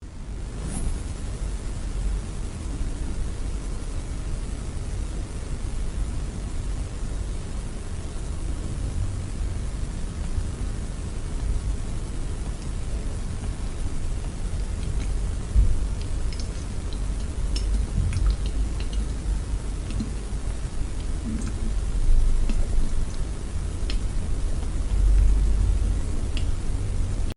Solar Static is a free sfx sound effect available for download in MP3 format.
yt_UP436-75l_c_solar_static.mp3